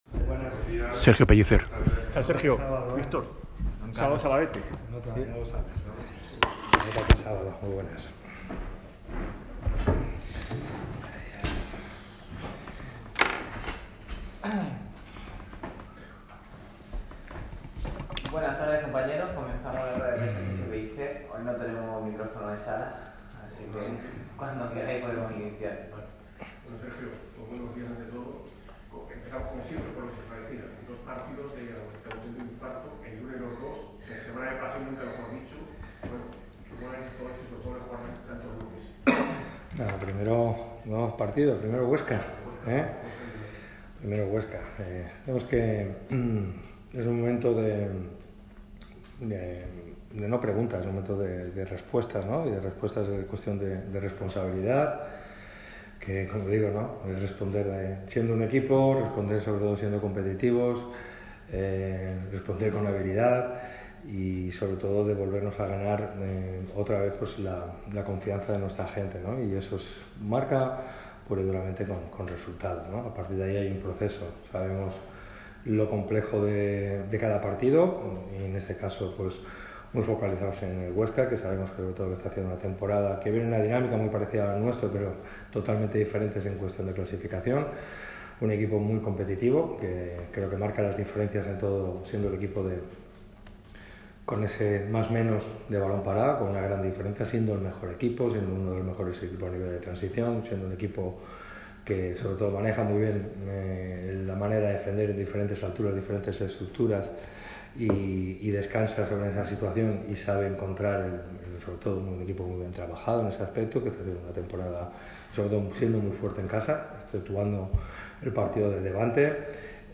El entrenador del Málaga CF ha comparecido en esta lluviosa mañana de sábado a modo de previa del duelo del lunes que enfrentará a los boquerones contra el SD Huesca en El Alcoraz.